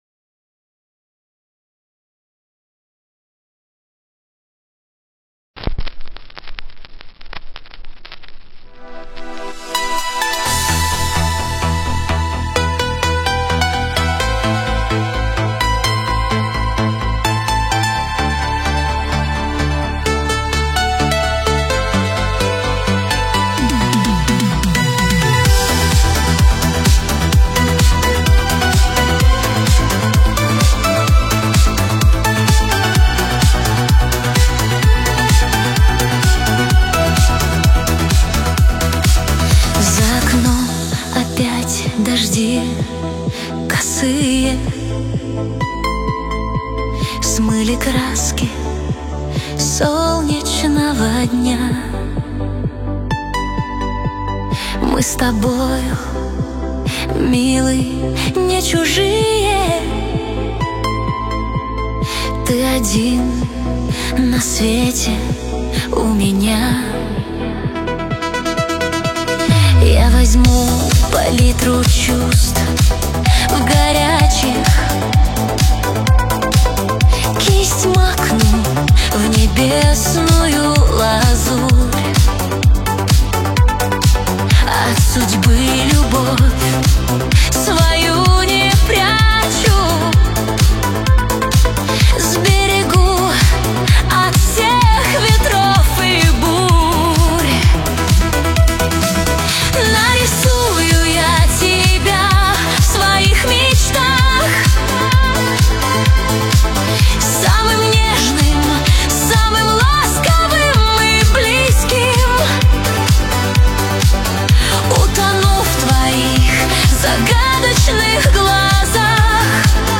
Красивая песня в стиле 90х